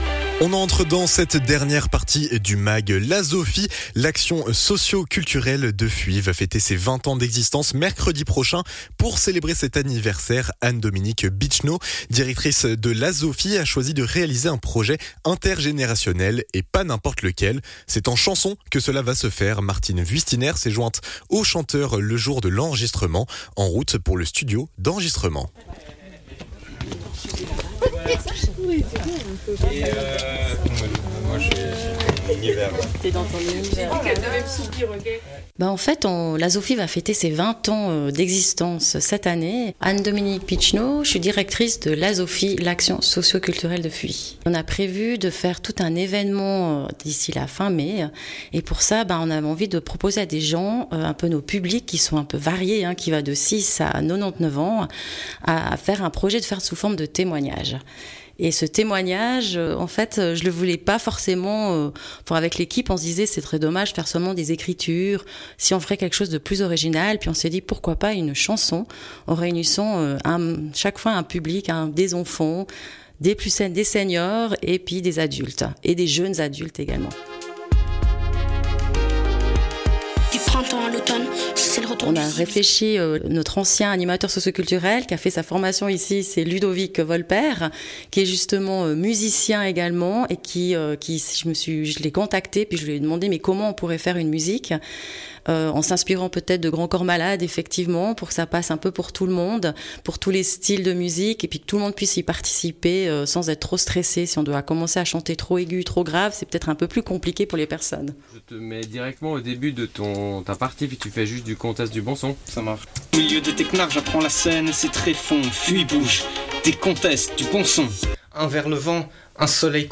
Découvrez les coulisses de cette création. En route pour le studio d'enregistrement.